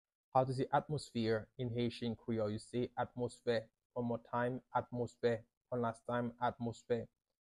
“Atmosphere” in Haitian Creole – “Atmosfè” pronunciation by a native Haitian tutor
“Atmosfè” Pronunciation in Haitian Creole by a native Haitian can be heard in the audio here or in the video below:
How-to-say-Atmosphere-in-Haitian-Creole-–-Atmosfe-pronunciation-by-a-native-Haitian-tutor.mp3